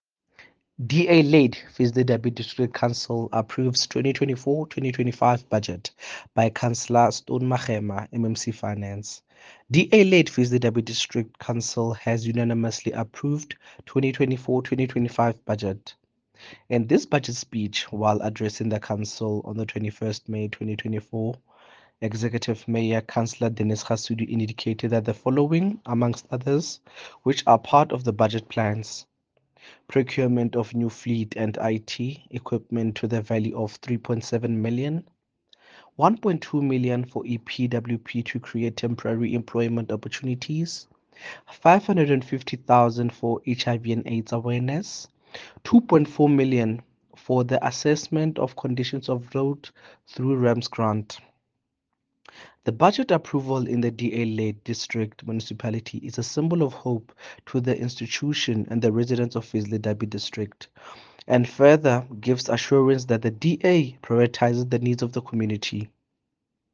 English soundbite by Cllr Teboho Thulo and
Eng-voice-Teboho.mp3